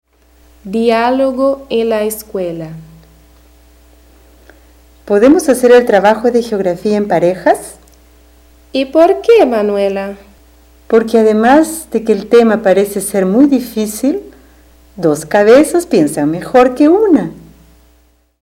Description: Áudio do livro didático Língua Espanhola I, de 2008. Diálogo com expressões populares.